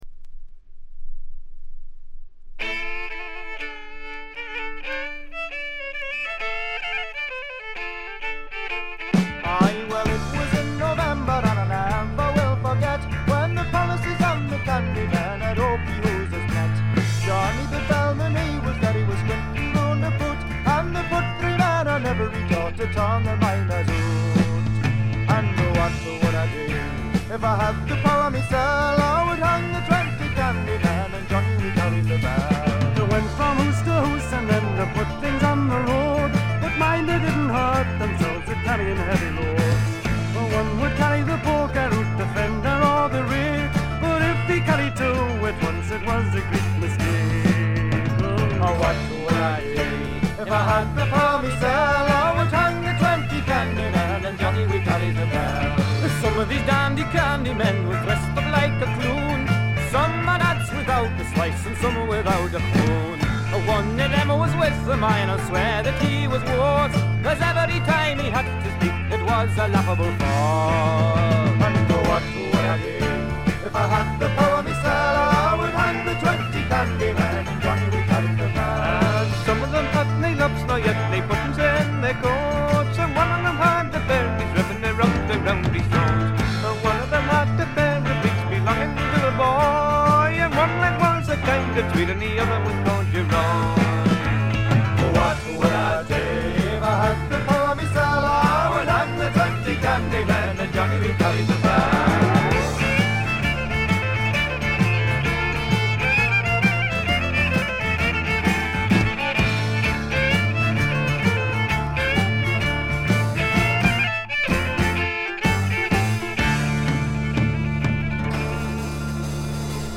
軽微なチリプチ程度。
フォークロックというよりもぐっとフェアポート寄りのエレクトリック・フォークを展開しています。
試聴曲は現品からの取り込み音源です。
Guitar, Mandolin, Harmonium, Bouzouki, Vocals
Drums, Vocals
Electric Bass, Acoustic Bass, Vocals
Violin, Guitar, Mandolin, Whistle, Vocals
Vocals, Guitar, Banjo, Mandolin